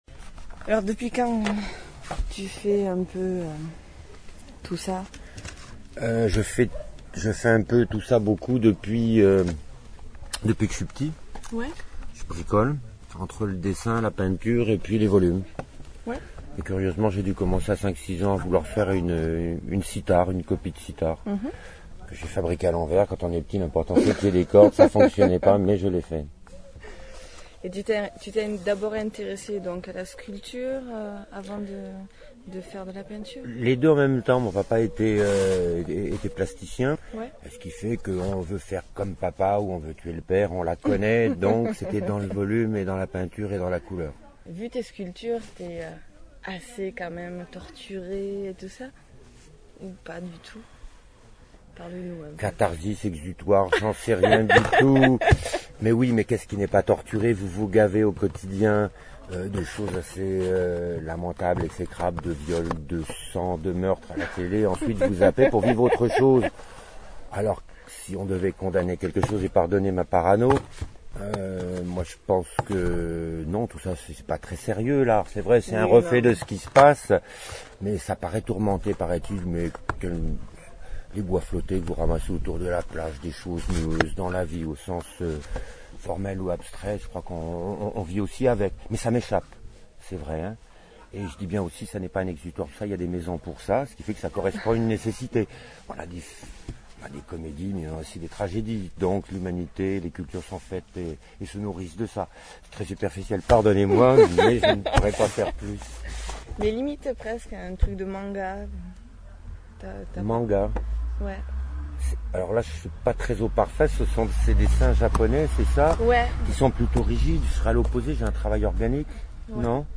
Étiquette : Interview